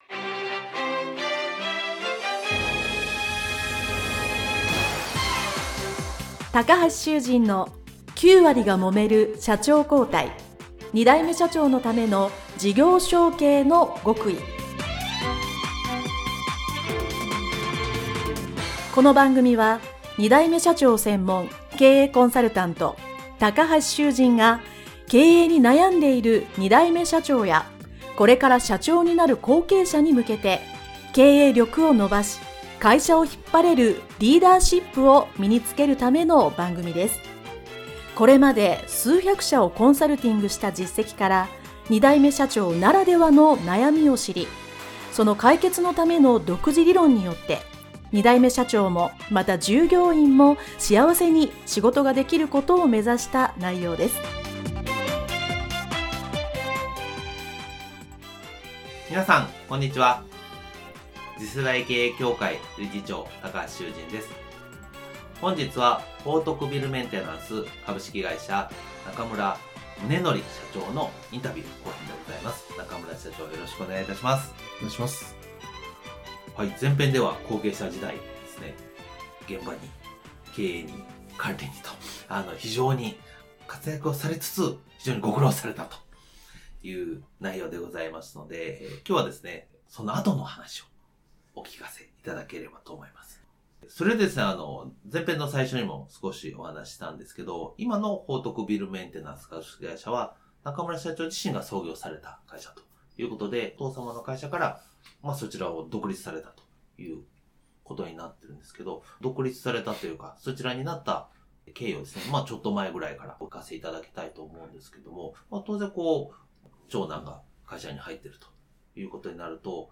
インタビュー後編